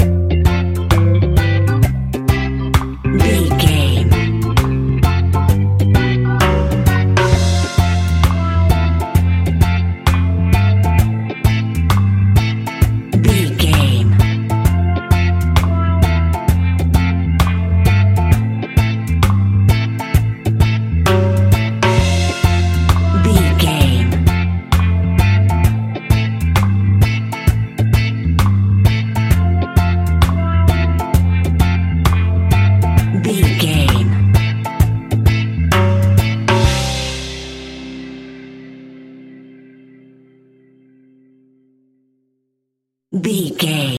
Classic reggae music with that skank bounce reggae feeling.
Ionian/Major
instrumentals
laid back
chilled
off beat
drums
skank guitar
hammond organ
percussion
horns